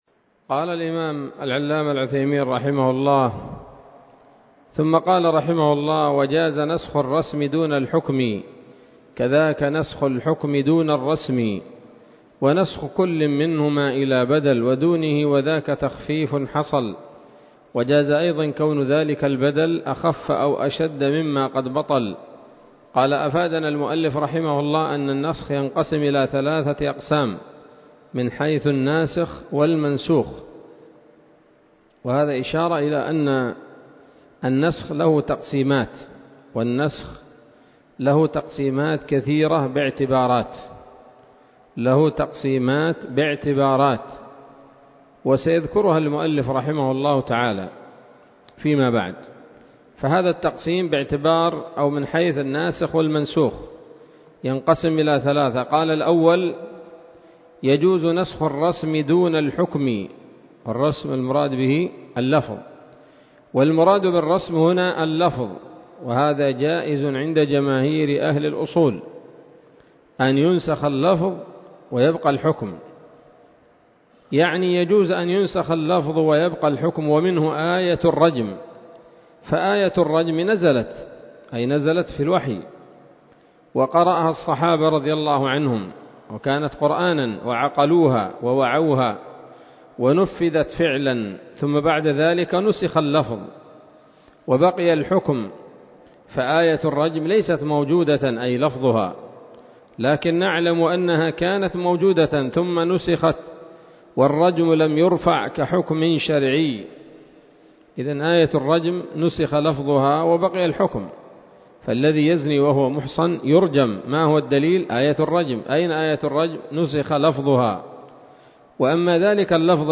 الدرس الخمسون من شرح نظم الورقات للعلامة العثيمين رحمه الله تعالى